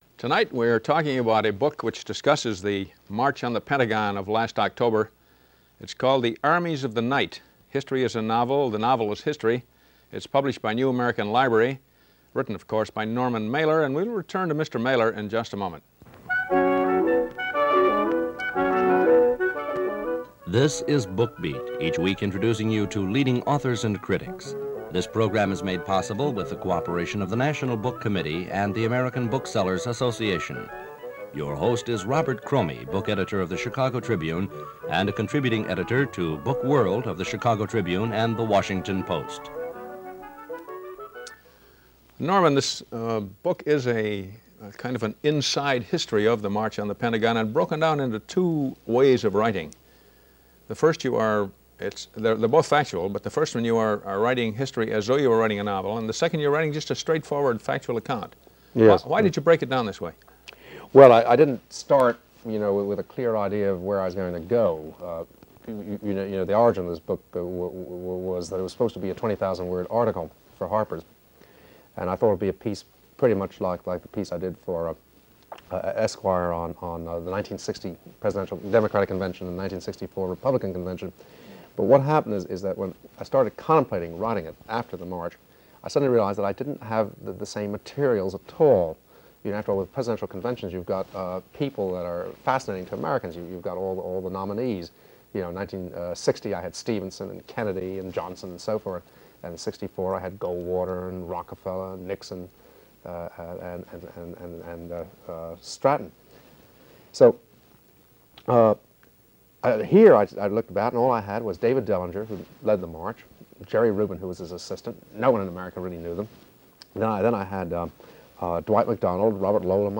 Norman Mailer and The Anti-War Movement - 1968 - Interview with author Norman Mailer in conjunction with his then-new novel, Armies Of The Night.
Normal-Mailer-Interview-1968.mp3